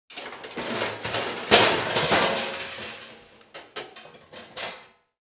OVEN-DRAWE_GEN-HDF18122.wav